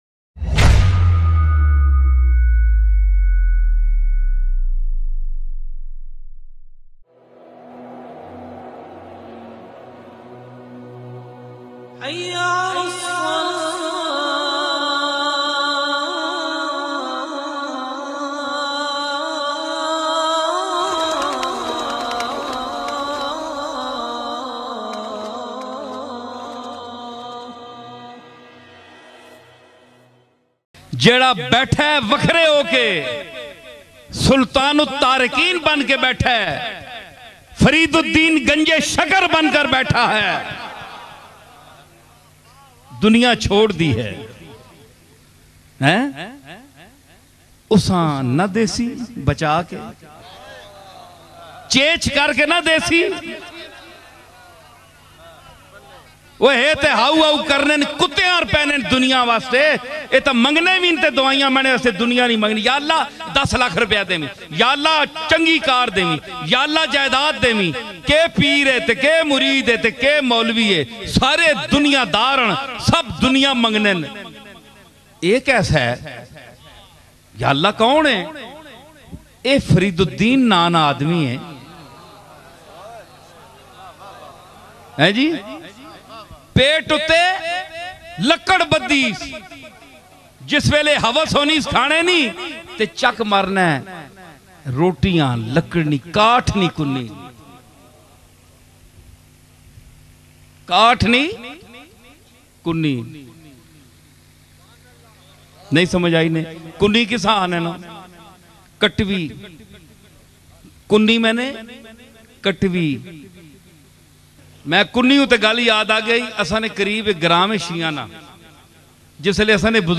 Amazing Story of Peer Mehr Ali Shah bayan mp3